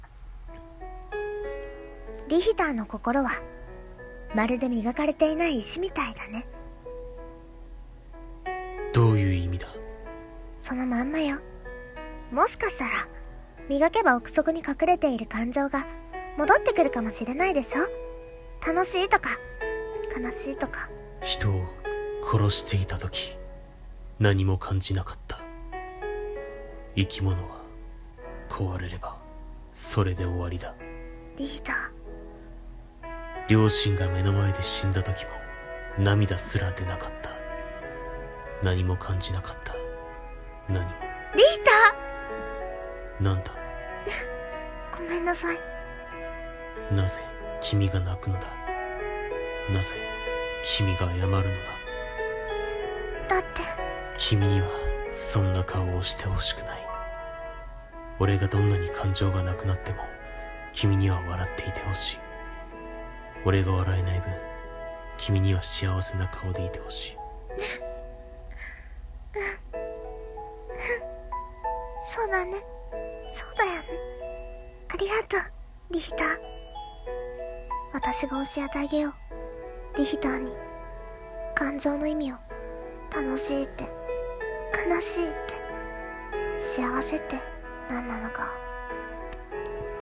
声劇台本｢Happiness｣